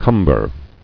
[cum·ber]